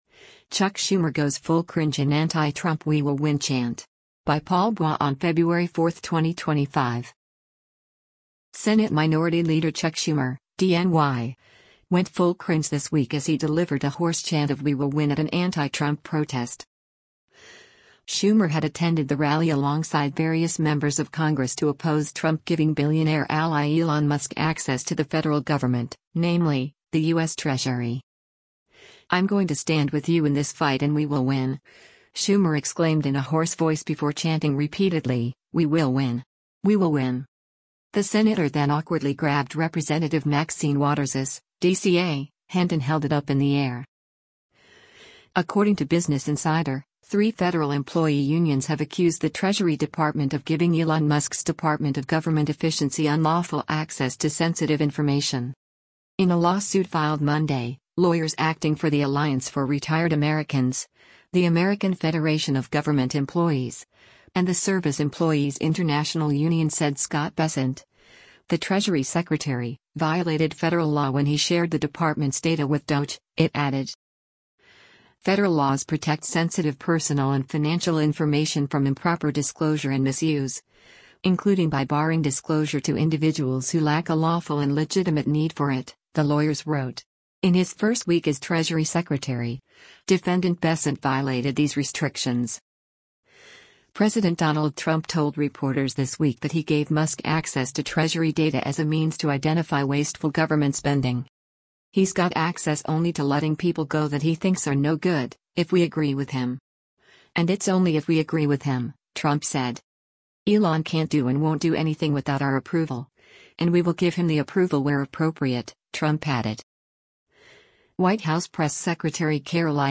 Senate Minority Leader Chuck Schumer (D-NY) went full cringe this week as he delivered a hoarse chant of “we will win” at an anti-Trump protest.
“I’m going to stand with you in this fight and we will win!” Schumer exclaimed in a hoarse voice before chanting repeatedly, “We will win! We will win!”